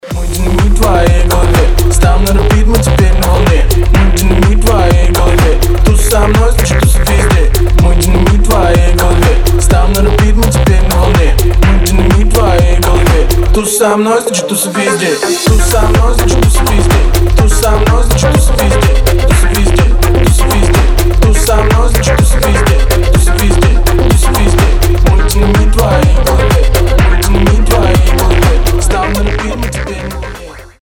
• Качество: 320, Stereo
ритмичные
мужской вокал
Драйвовые
Хип-хоп
dance
club
динамичные